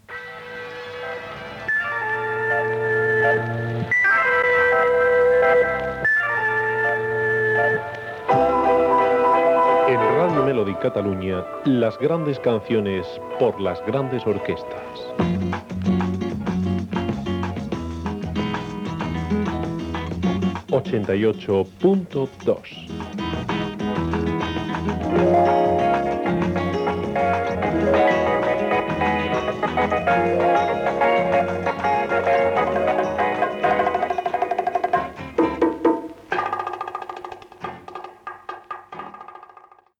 Identificació del programa i de l'emissora.
Musical